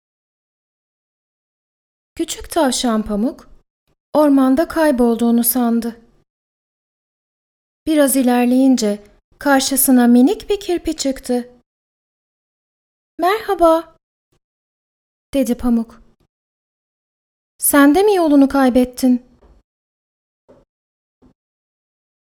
Turkish voice artist, warm, trustworthy, and expressive tone. Her voice blends clarity with natural emotion, creating a genuine connection with the listener.
VOICE ACTOR DEMOS
Words that describe my voice are Warm, Trustworthy, Professional.